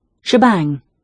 Det datorgenererade uttalet för ordet 'rasket' är helt fel, det är ett sj-ljud men ordet ska ju uttalas som det stavas (med hörbart s och k).